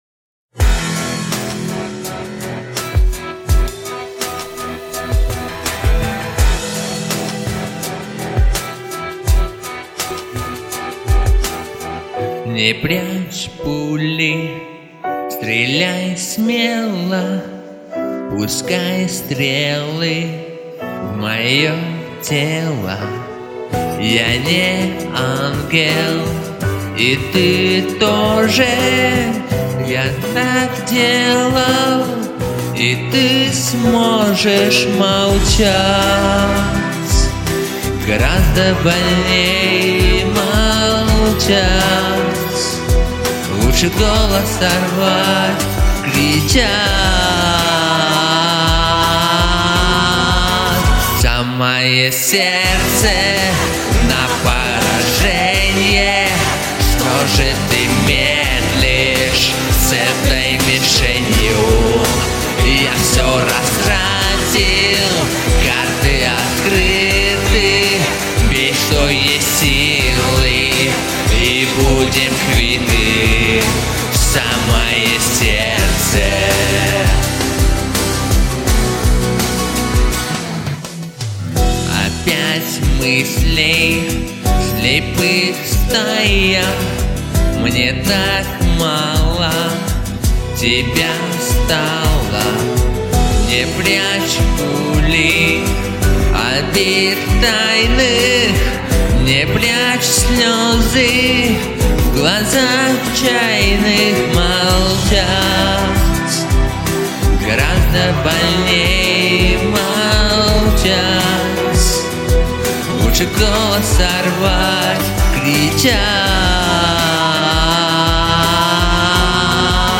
немного в ноты иногда не попадаете